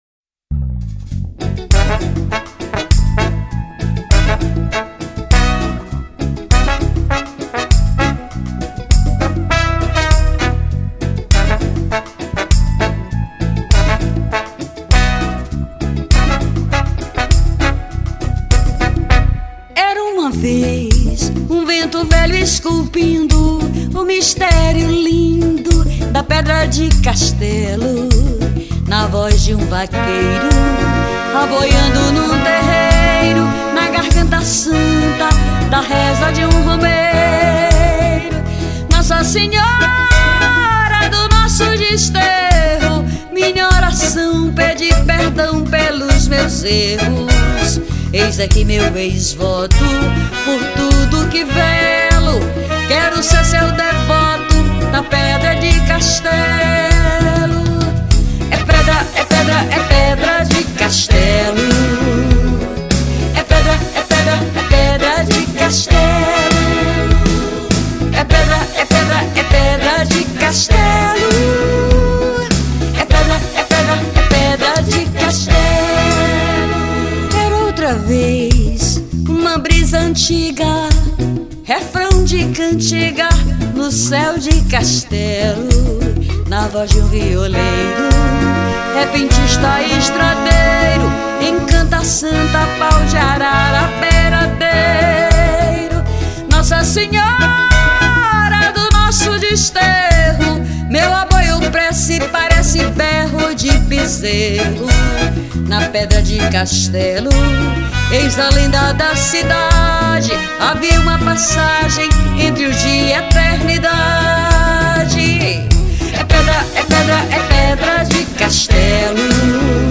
03:54:00   Reggae